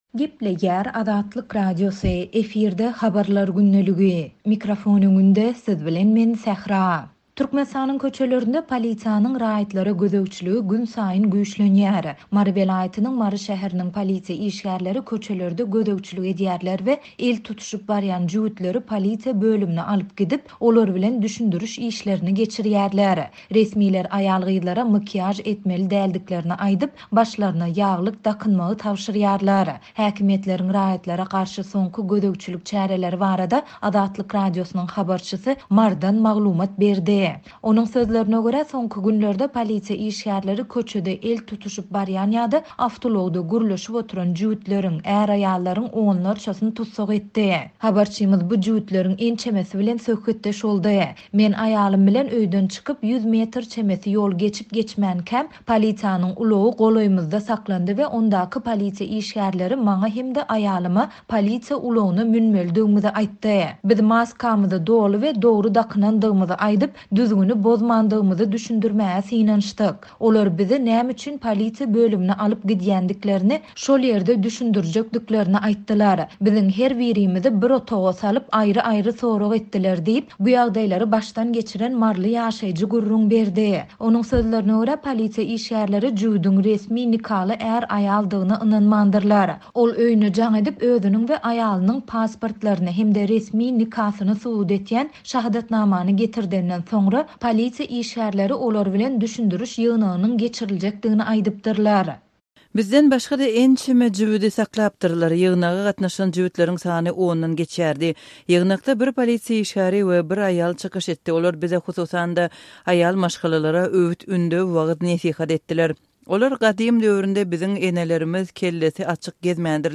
Häkimiýetleriň raýatlara garşy soňky gözegçilik çäreleri barada Azatlyk Radiosynyň habarçysy Marydan habar berdi.